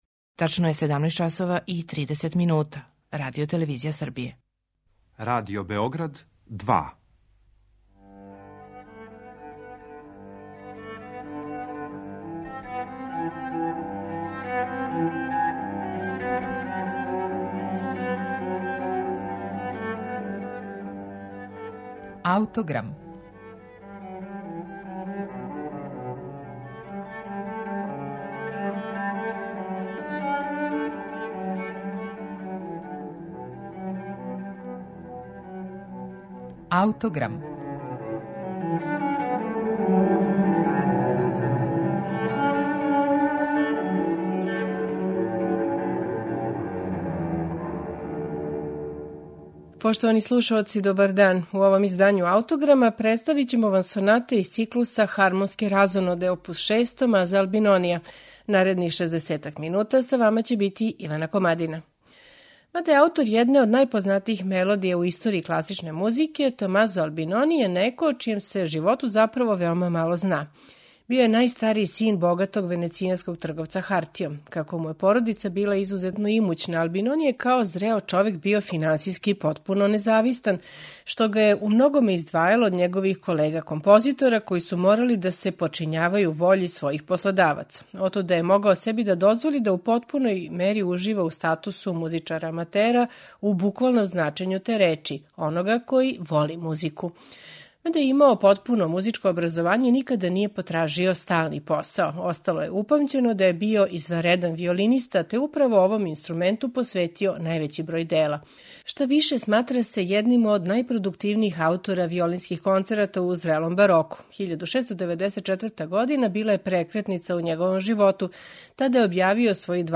У 12 соната за виолину, које чине ову збирку, Албинони је показао сво мајсторство које је поседовао не само као композитор, већ и као виртуозни виолиниста.
на оригиналним инструментима епохе
виолина
виолончело
оргуље